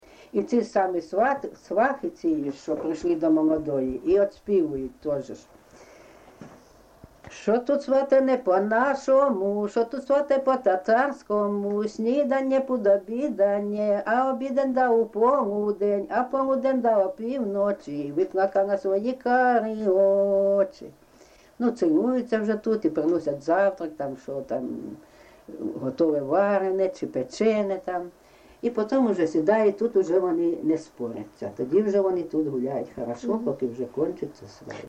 ЖанрВесільні
Місце записус. Курахівка, Покровський район, Донецька обл., Україна, Слобожанщина